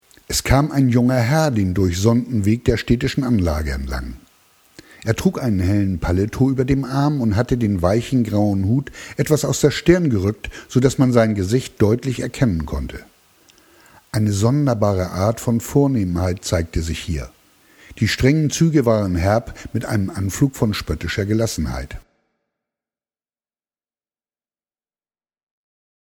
Schauspieler -Sprecher-Autor
norddeutsch
Sprechprobe: Sonstiges (Muttersprache):
voice over german